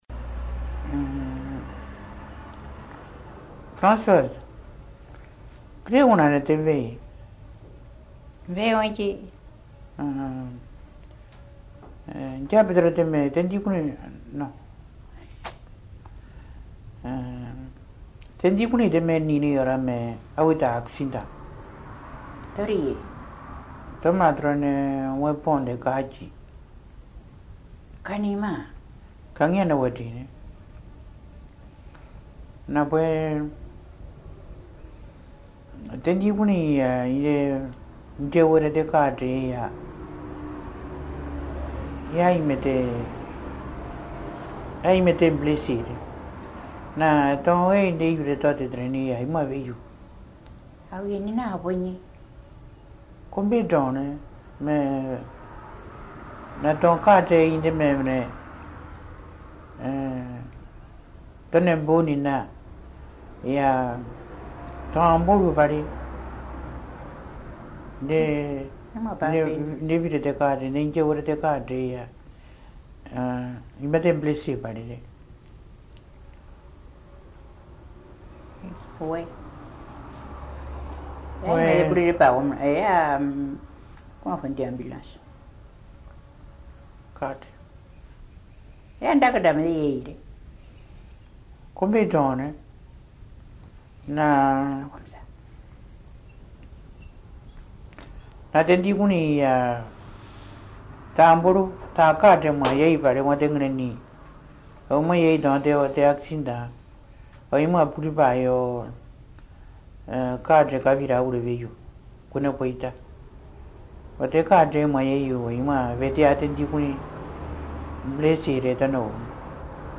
Accueil > Dialogue > Dialogue > Drubéa